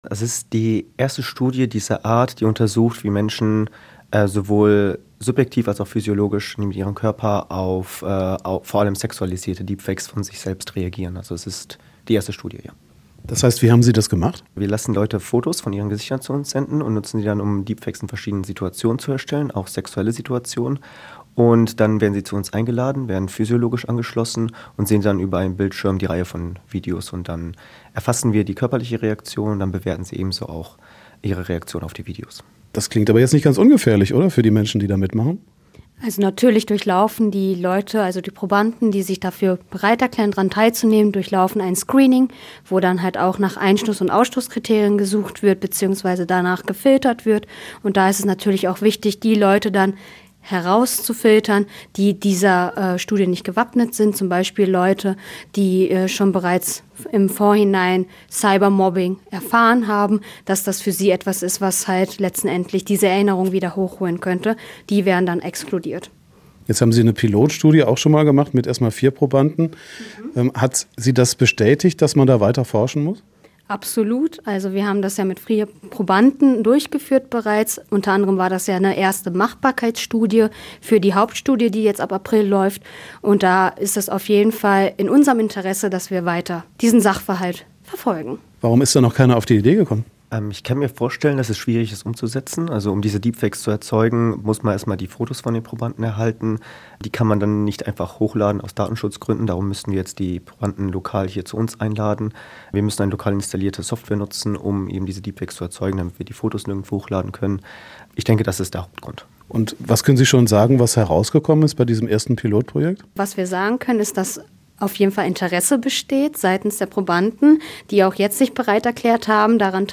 Forscher im Interview mit Radio Essen